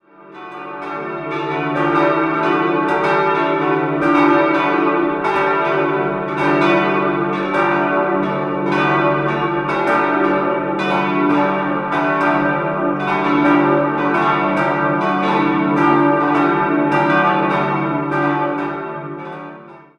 Aus dem Vorgängerbau wurde unter Anderem der klassizistische Hochaltar von 1785 übernommen. 4-stimmiges ausgefülltes D-Dur-Geläute: d'-e'-fis'-a' Die drei großen Glocken wurden 1950 von Johann Hahn in Landshut gegossen, die kleine stammt noch von Karl Hamm, Regensburg, aus dem Jahr 1910.